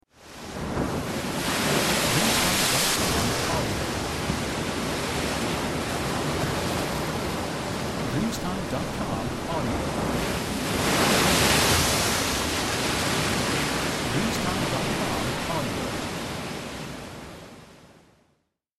Starke Wellen von einem stürmischen Meer